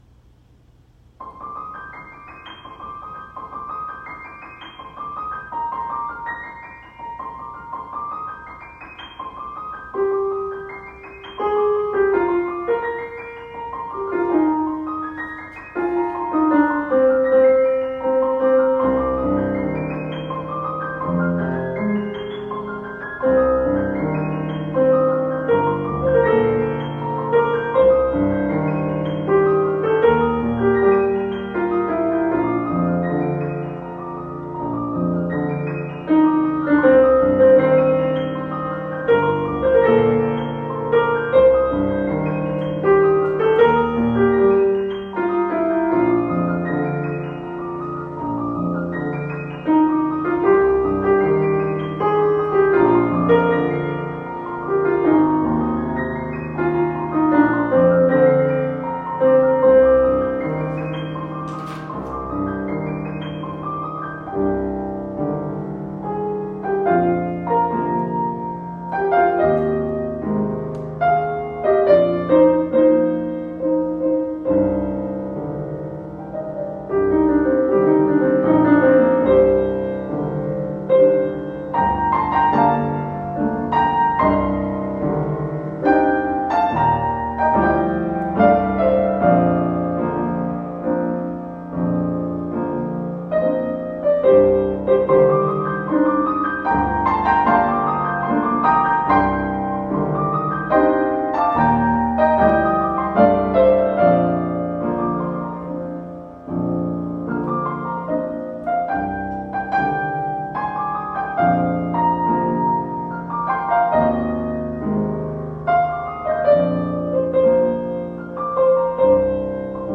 Piano duet
An Irish love song